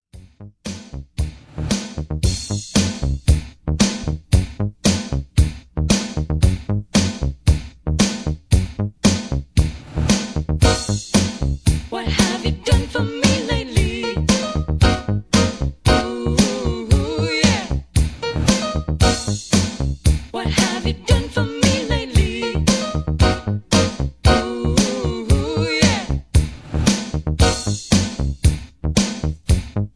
Key-Ebm) Karaoke MP3 Backing Tracks
Just Plain & Simply "GREAT MUSIC" (No Lyrics).